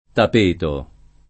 tap%to]: Le porpore e i tapeti alessandrini [